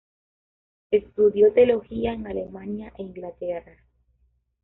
te‧o‧lo‧gí‧a
/teoloˈxia/